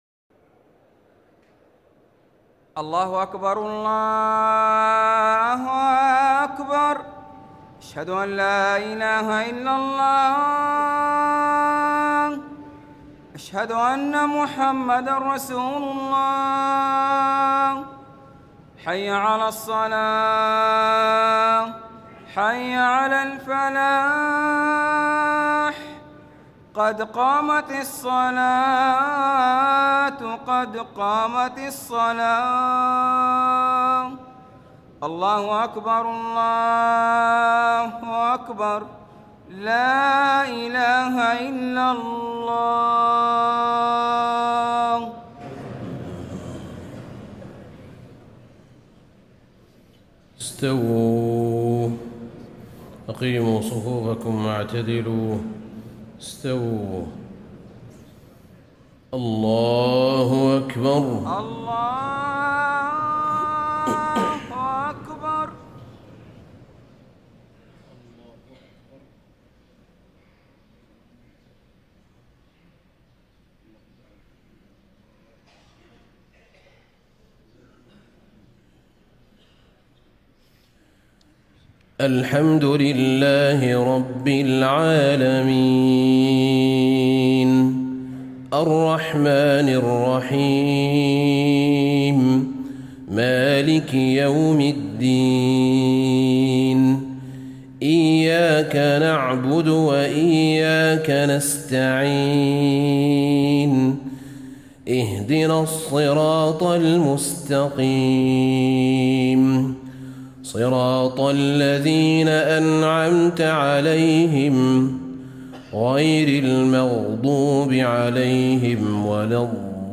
صلاة المغرب 8-5-1435 سورتي الزلزلة والعاديات > 1435 🕌 > الفروض - تلاوات الحرمين